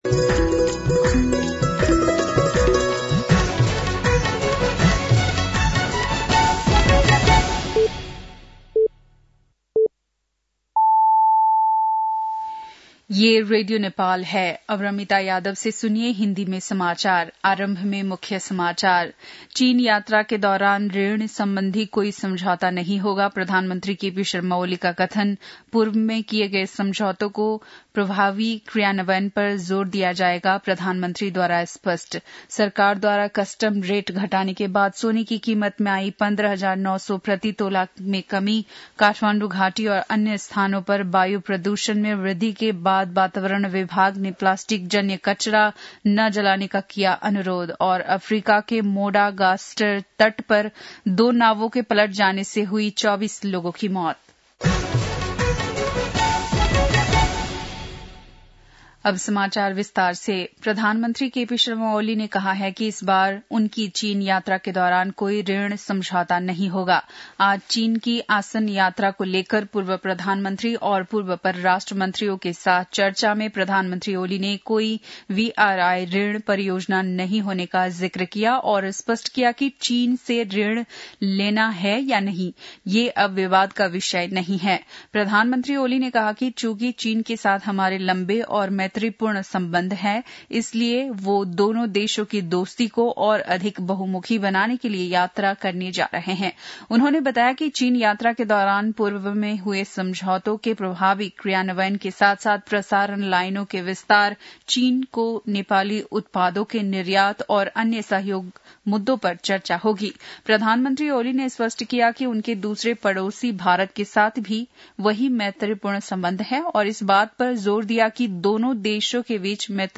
बेलुकी १० बजेको हिन्दी समाचार : ११ मंसिर , २०८१